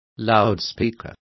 Also find out how bafle is pronounced correctly.